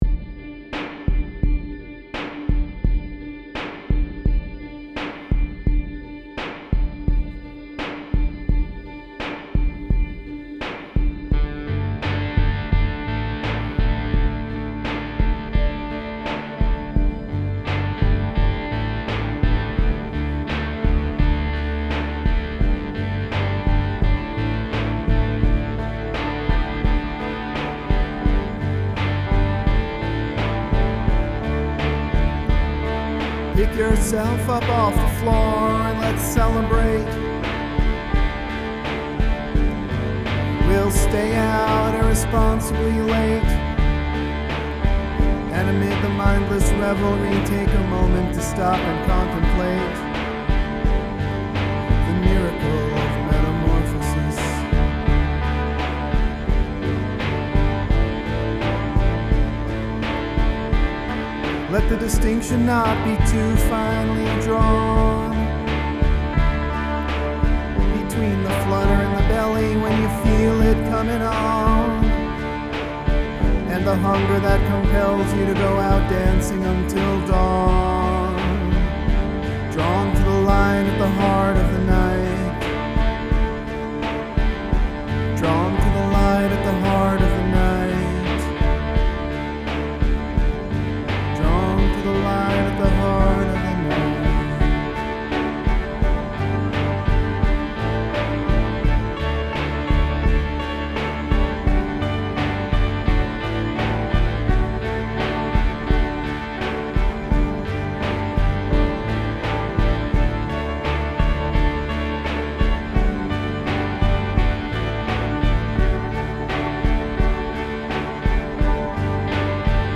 Noisy Vocal and Instrumental Pop Music
guitar, vocal, electronics